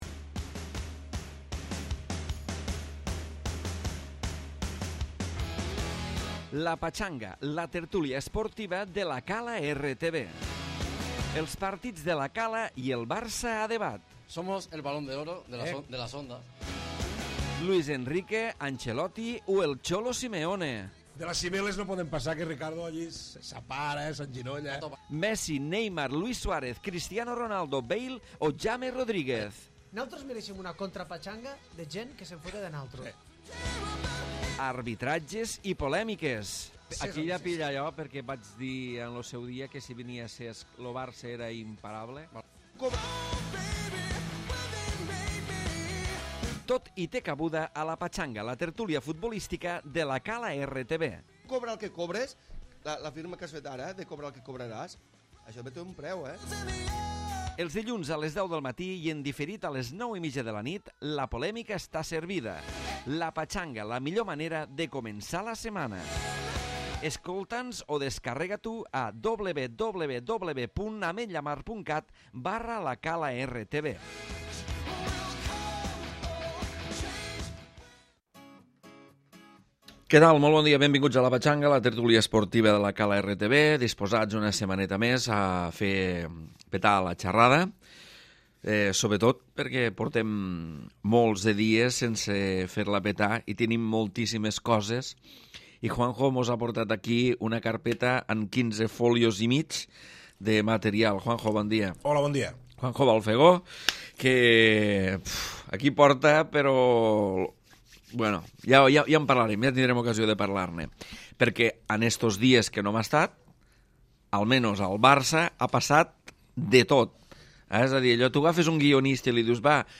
Tertúlia esportiva de temàtica futbolística amb l'actualitat del 1er equip de La Cala i del Barça.